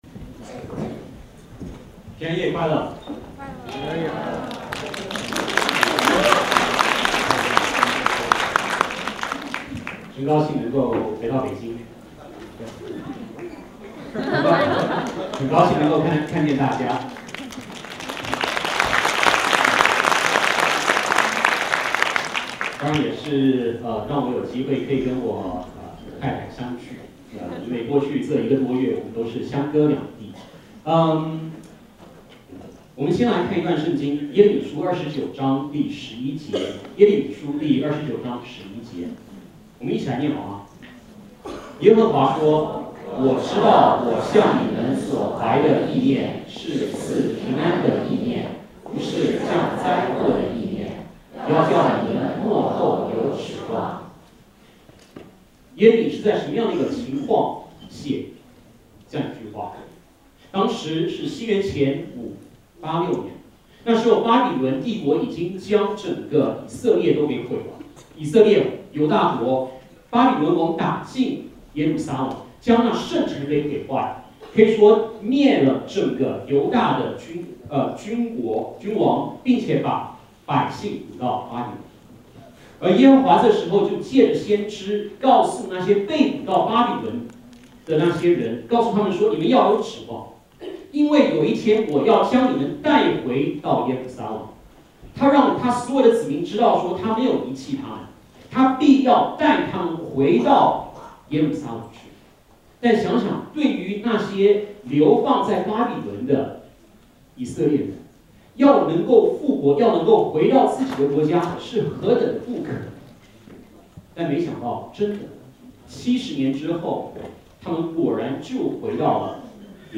主日证道 |  哪里有平安 – BICF – Beijing International Christian Fellowship